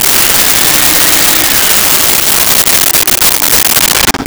Shop Vac On And Off
Shop Vac On and Off.wav